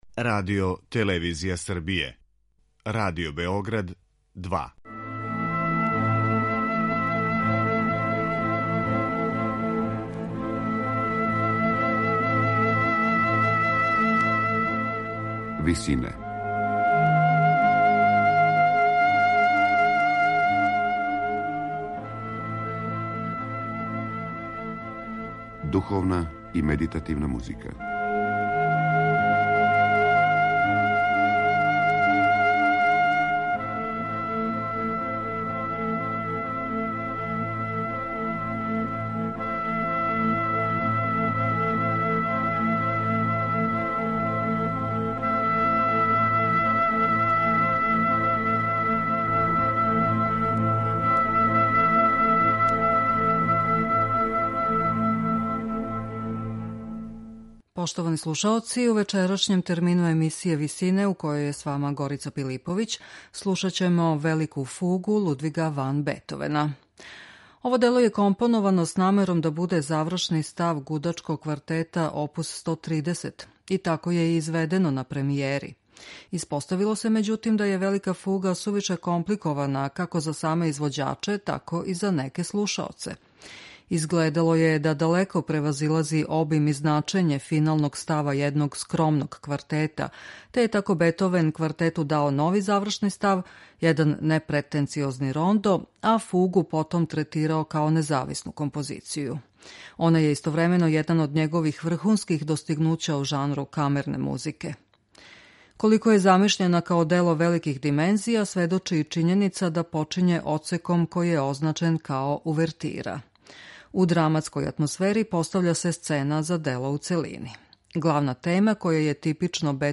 Она је истовремено један од његових врхунских достигнућа у жанру камерне музике.